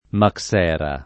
[ mak S$ ra ]